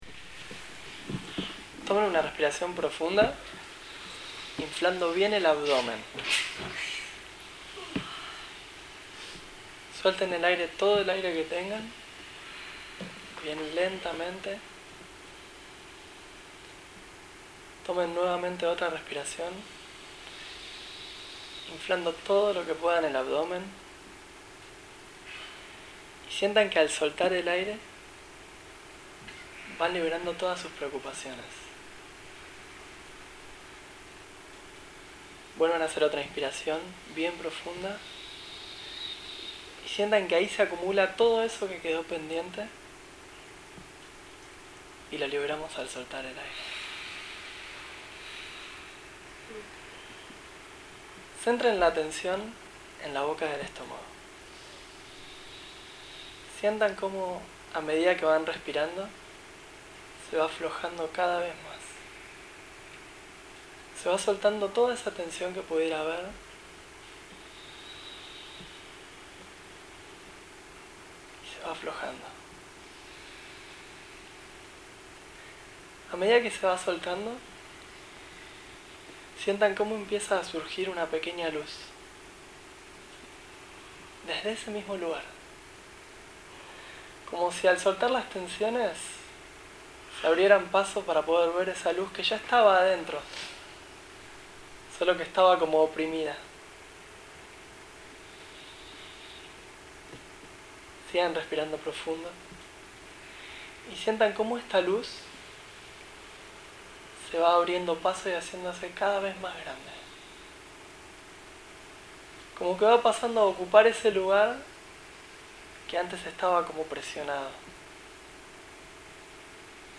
Meditación gratuita en Buenos Aires – Tema: Liberando emociones de todo el cuerpo desde el 3er chakra
En Capital Federal, Argentina.